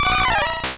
Cri de Colossinge dans Pokémon Rouge et Bleu.